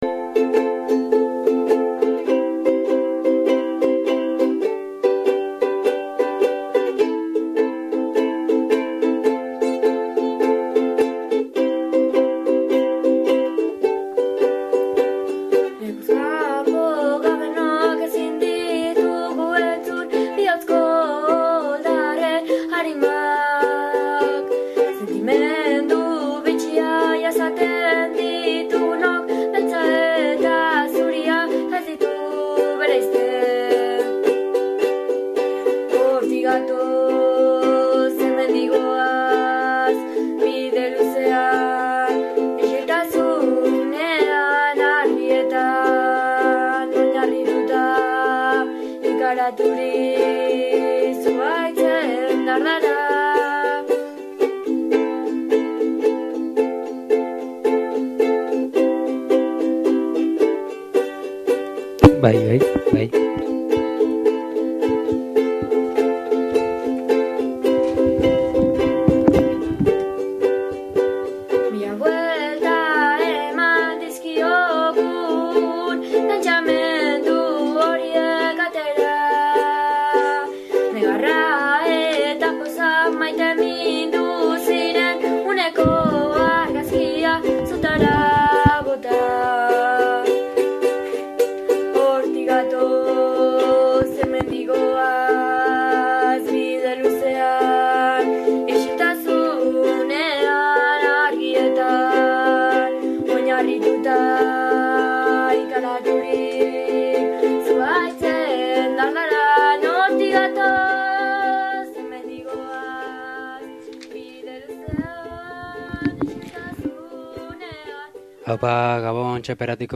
Gaurko gurasoak saioan gai eta ahots ugari izan ditugu.